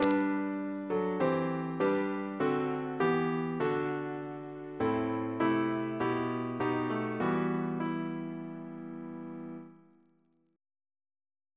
Single chant in E minor Composer: Tonus Peregrinus Reference psalters: ACB: 286; CWP: 152; OCB: 31; PP/SNCB: 186; RSCM: 212